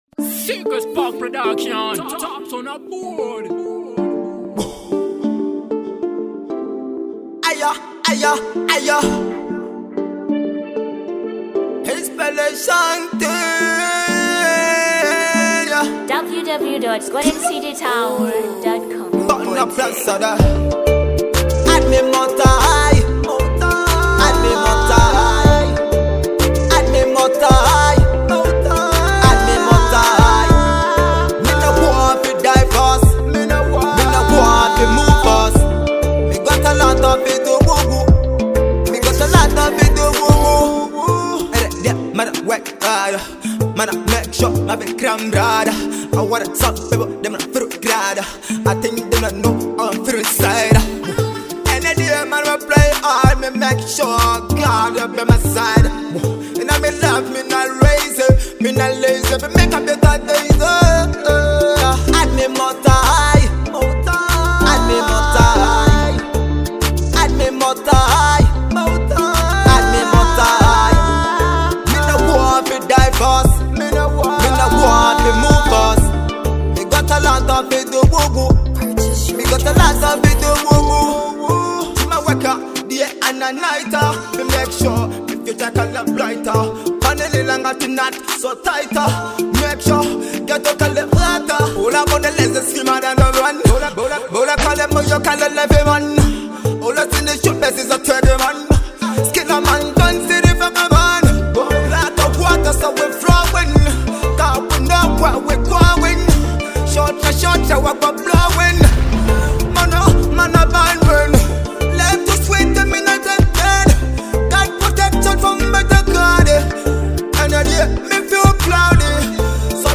2. Dancehall